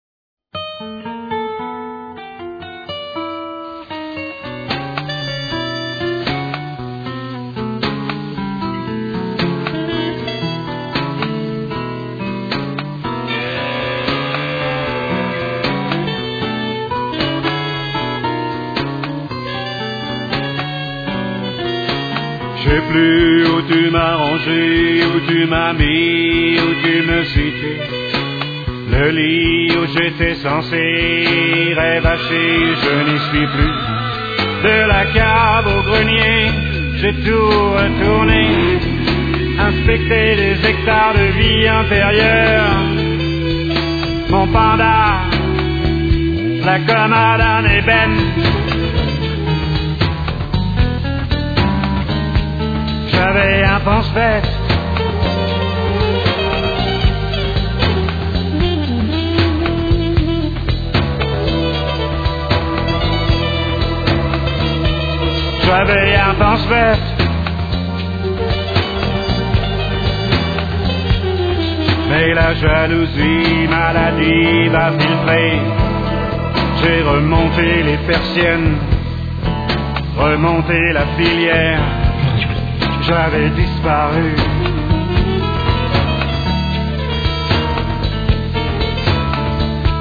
ajoute une résonance Jazz qui n'est pas pour me déplaire !